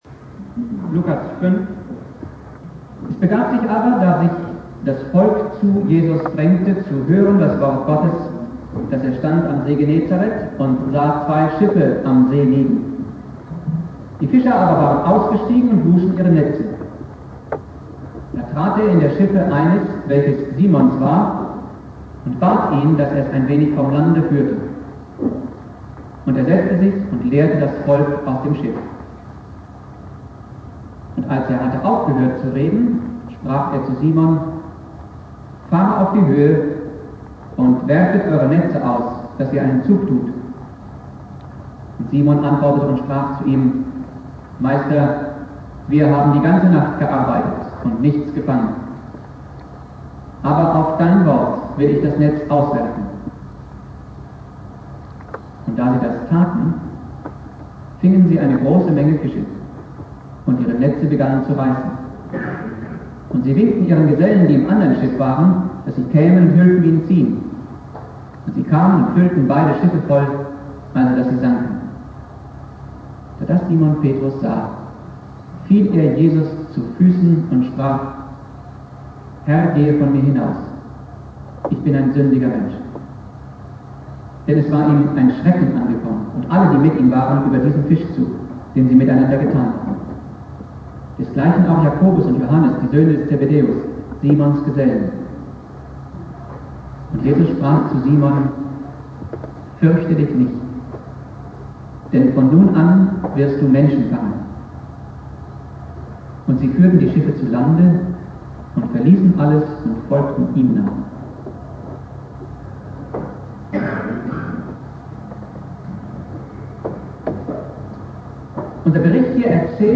Predigt
Thema: "Fischzug des Petrus" (mit Außenmikro aufgenommen) Bibeltext